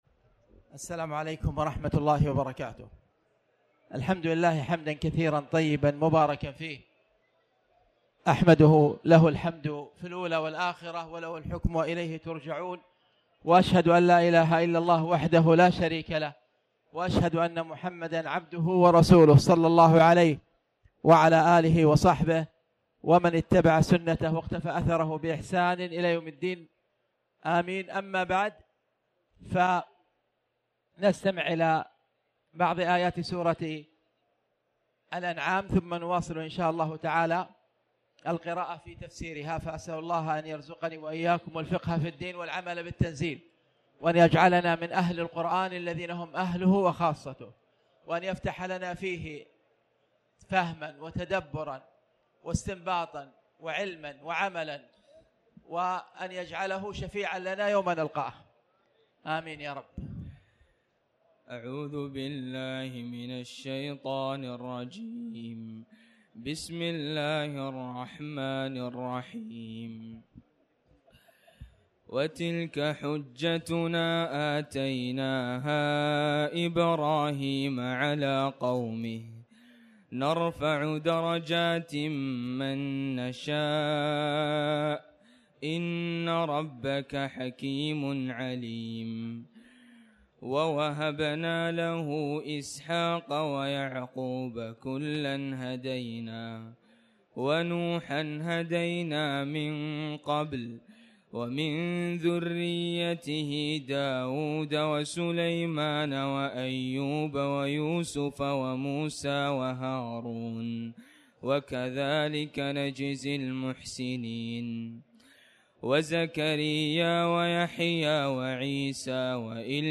تاريخ النشر ٢٨ رمضان ١٤٣٩ هـ المكان: المسجد الحرام الشيخ